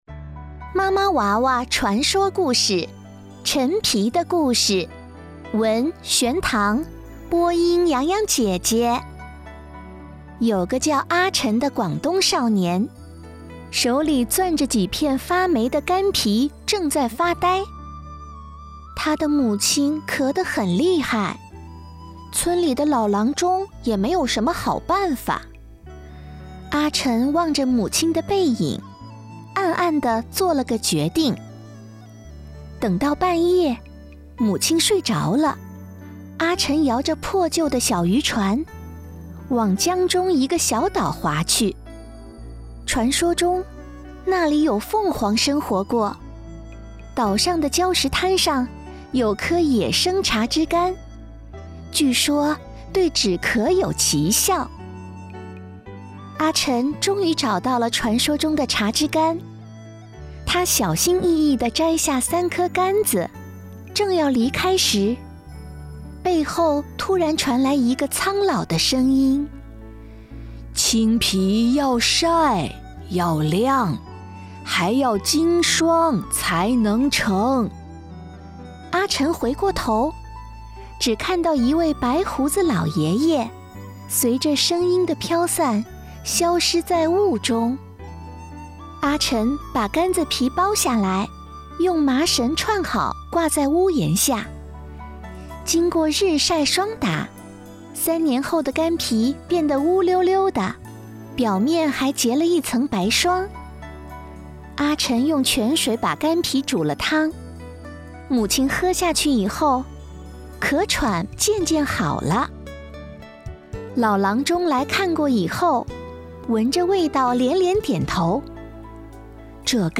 故事播讲